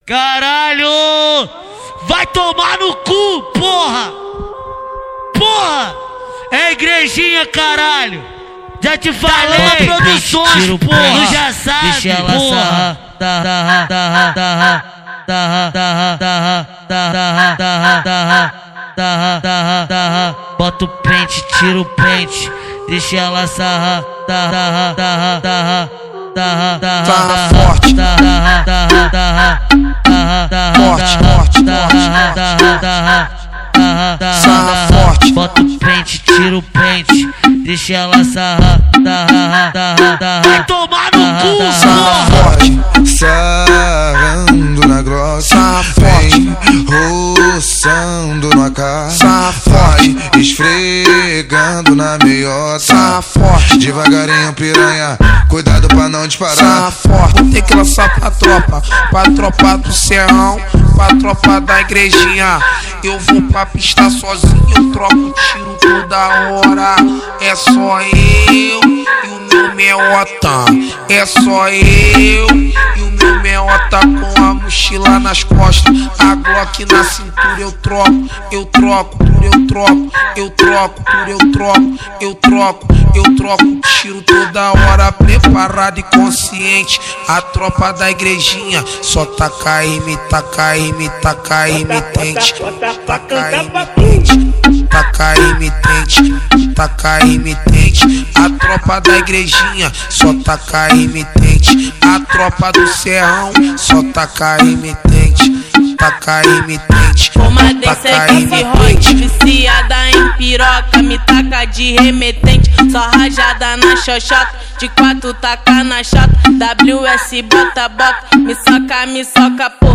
Gênero: Phonk